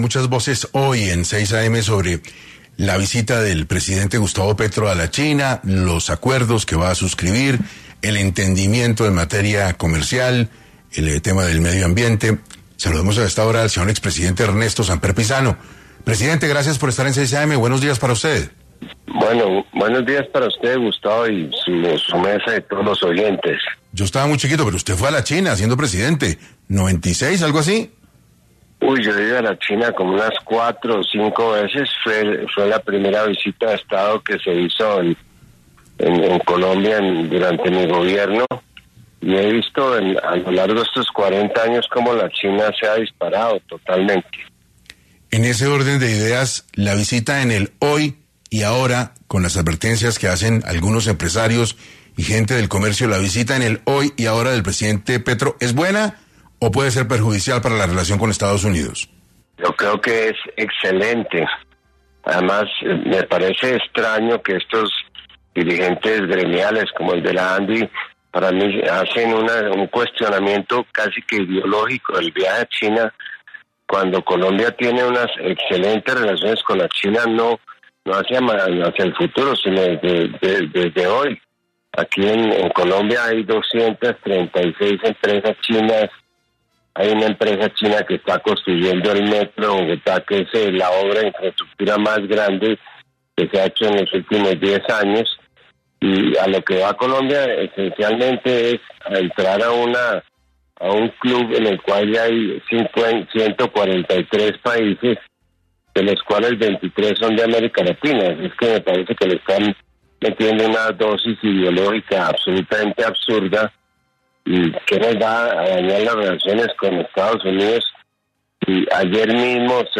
Ernesto Samper, expresidente de Colombia, estuvo en 6AM para hablar de la visita de Petro a China.
En este orden de ideas, el expresidente Ernesto Samper, pasó por los micrófonos de 6AM para dar sus opiniones frente a esta visita del presidente Petro al gigante asiático.